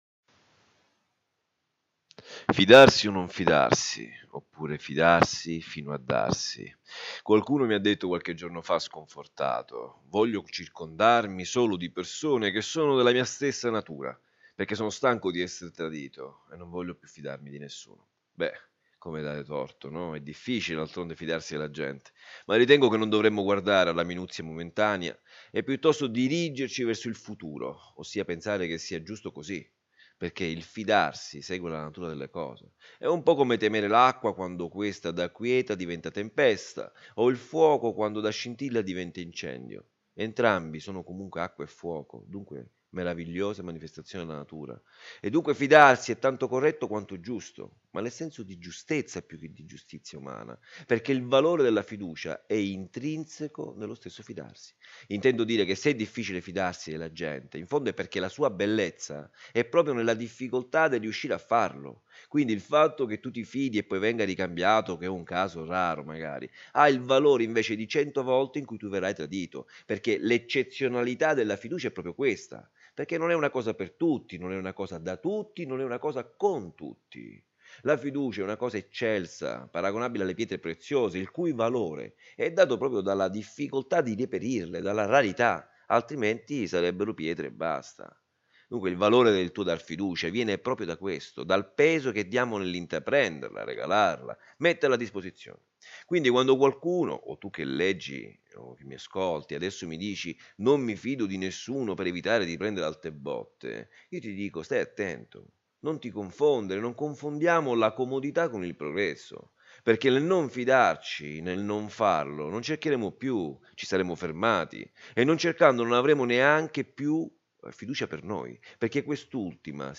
I minuti dei file audio riproducono le riflessioni degli articoli a voce alta, perché abbiano accesso all’ascolto i ciechi e quelli tra noi che pur avendo la vista sono diventati non vedenti,